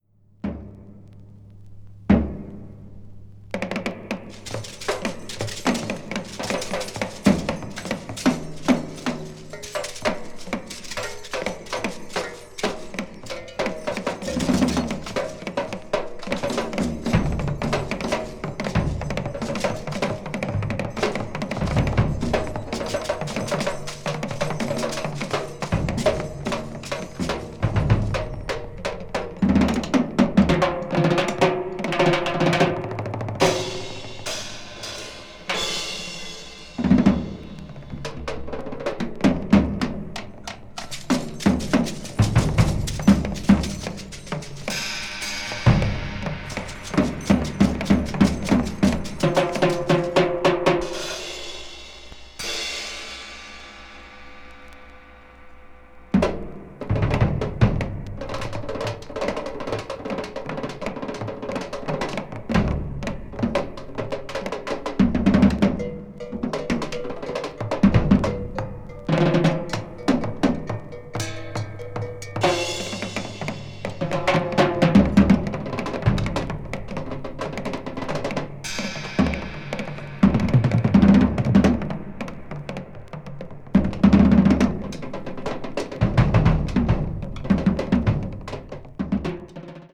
お互いの呼吸や放出されるエネルギーの交感が生み出すダイナミズムが見事に調和していく、素晴らしいアンサンブル。
avant-jazz   ethnic jazz   free improvisation   free jazz